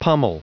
Prononciation du mot pummel en anglais (fichier audio)
Prononciation du mot : pummel
pummel.wav